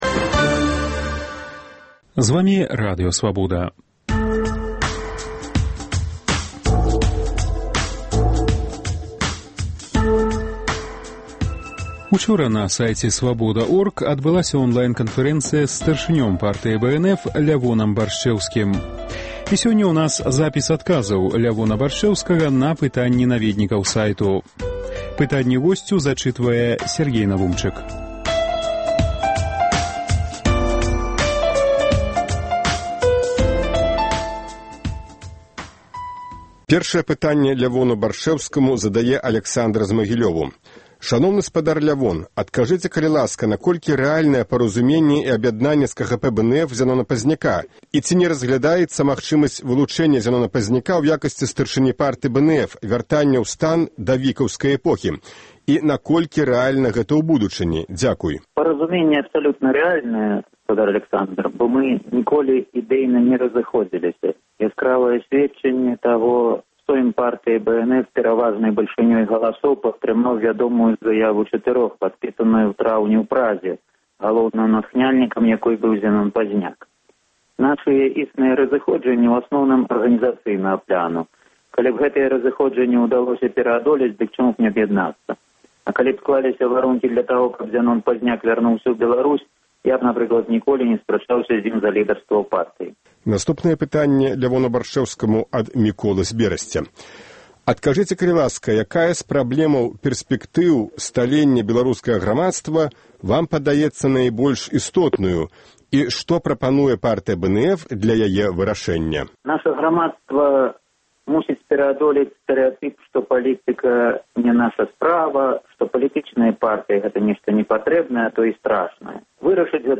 Онлайн- канфэрэнцыя
Радыёварыянт онлайн-канфэрэнцыі са старшынём Партыі БНФ Лявонам Баршчэўскім.